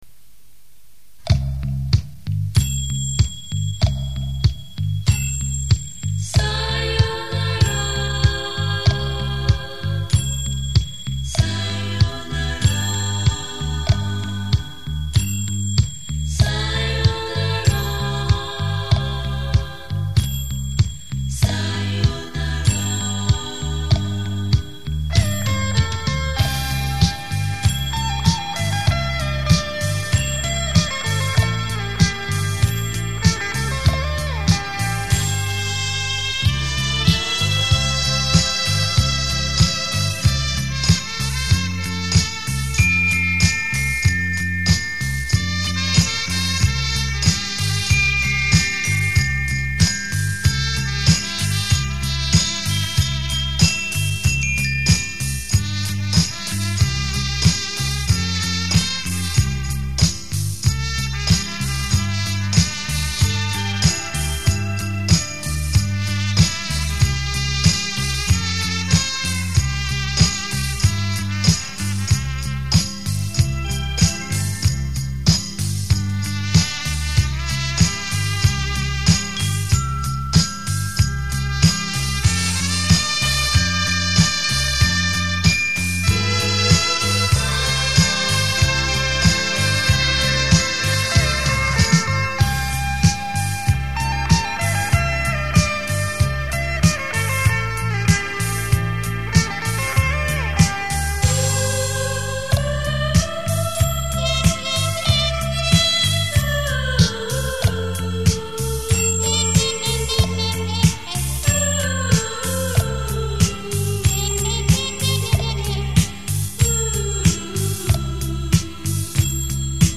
卡带数字化
类型：纯音乐